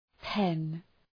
pen Προφορά
{pen}